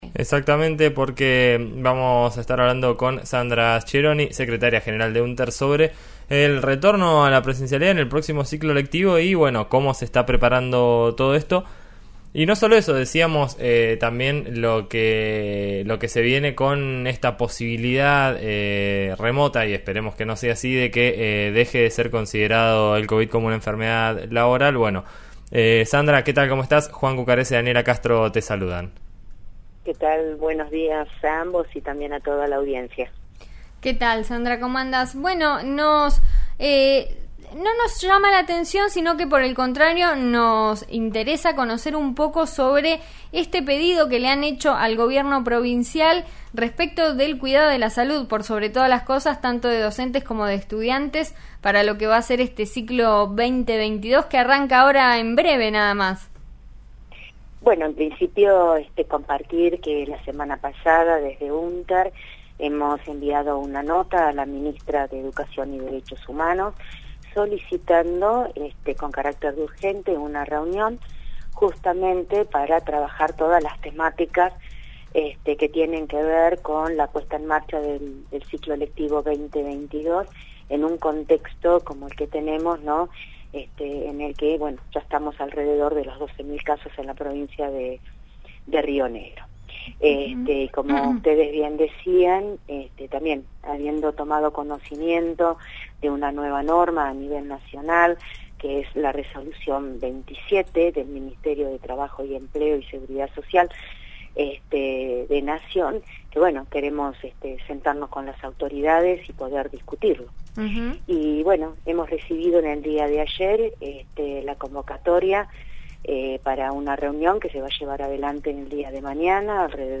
indicó al aire de «En Eso Estamos» por RN RADIO (89.3 de Neuquén) que